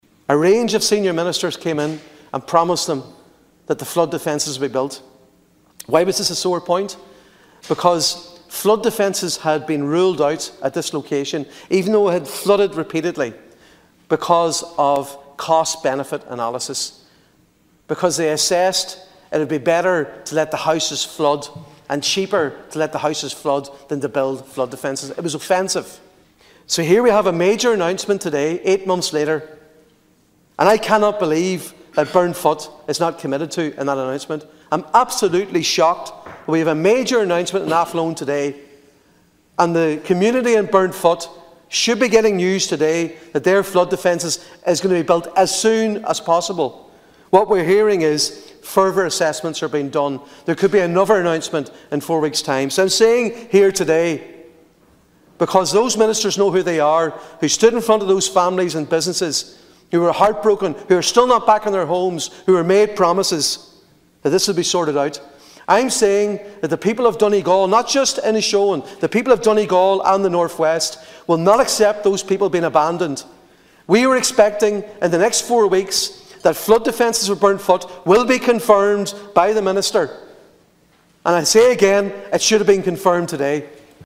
However, speaking in the Seanad a short time ago, Senator Padraig Mac Lochlainn criticised the Government for not including Burnfoot in today’s announcement.